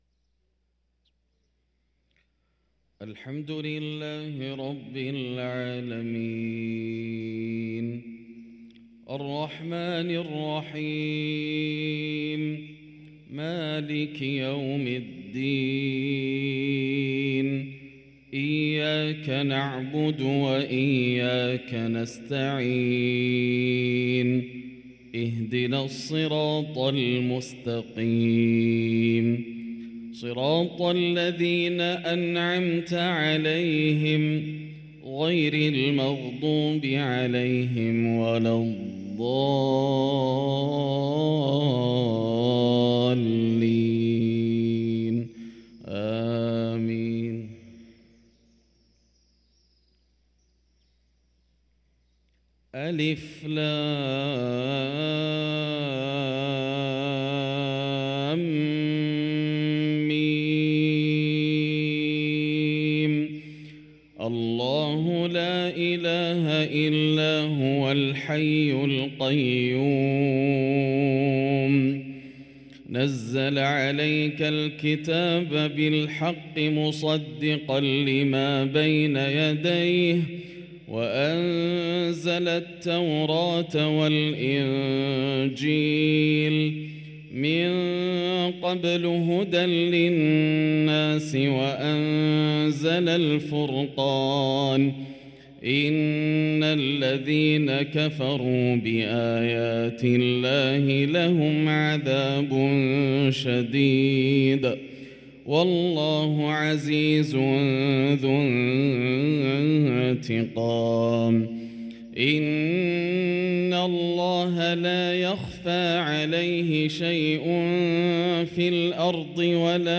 صلاة الفجر للقارئ ياسر الدوسري 6 شعبان 1444 هـ